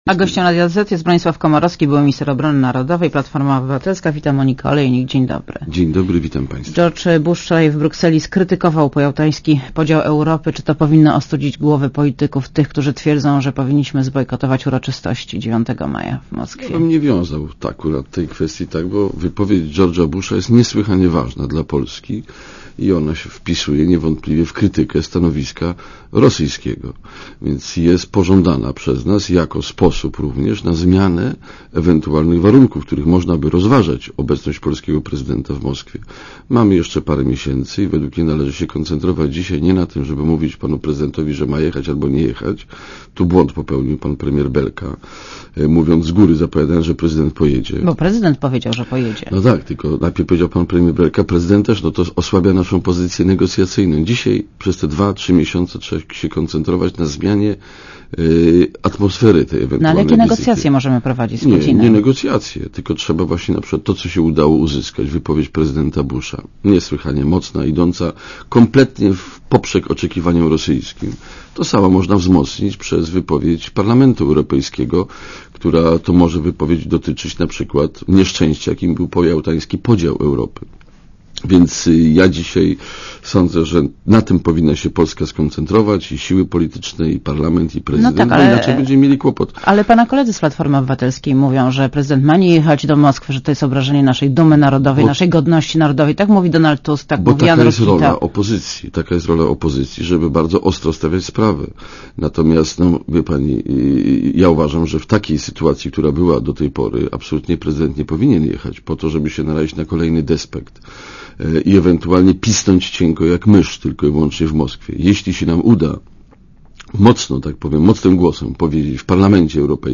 Monika Olejnik rozmawia z Bronisławem Komorowskim, posłem PO, byłym ministrem obrony narodowej